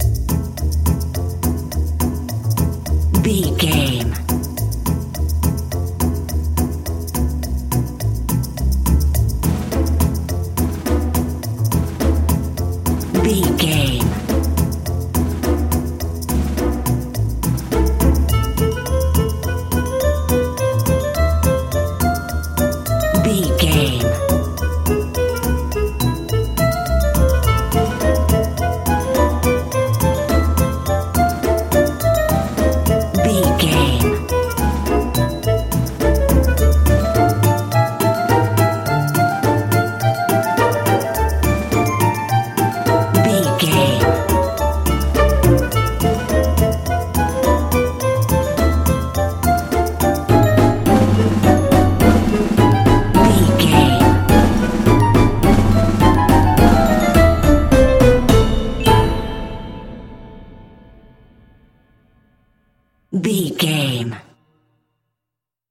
Aeolian/Minor
percussion
strings
double bass
synthesiser
silly
circus
goofy
comical
cheerful
perky
Light hearted
secretive
quirky